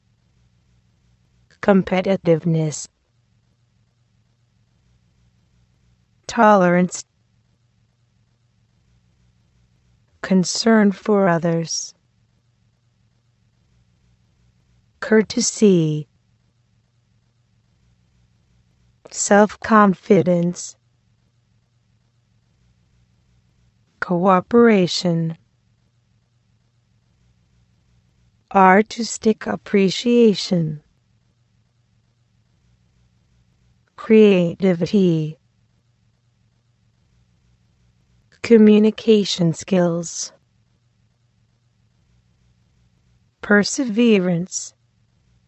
This glossary focuses on various nouns that describe personal qualities and characteristics. Please listen and repeat twice.